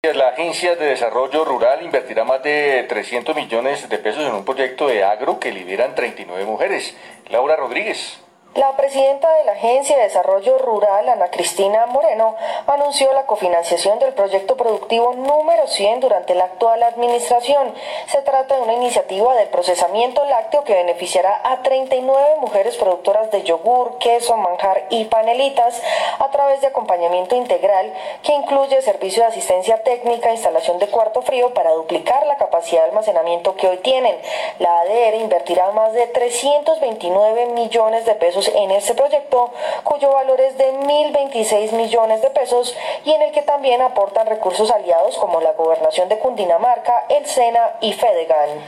Entrevista, Presidente ADR "Proyecto 100 Guatavita" RCN Radio.